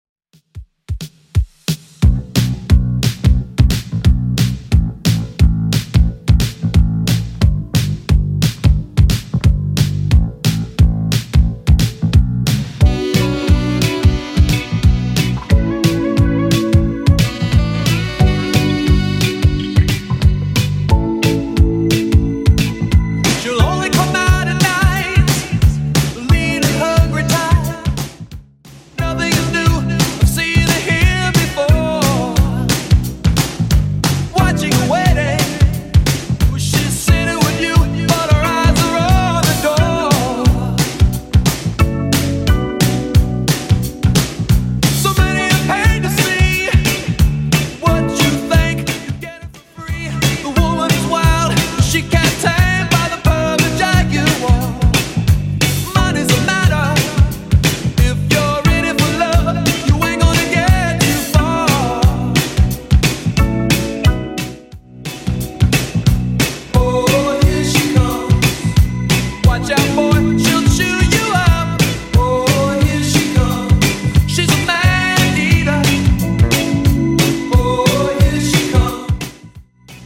Genre: 90's
BPM: 135